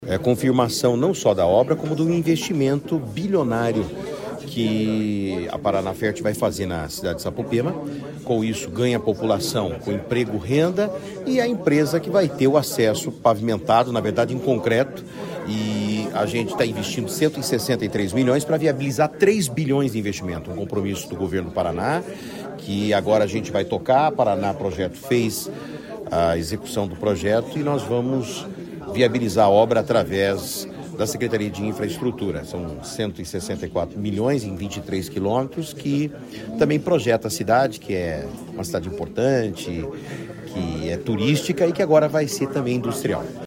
Sonora do secretário de Infraestrutura e Logística, Sandro Alex, sobre a rodovia de concreto em Sapopema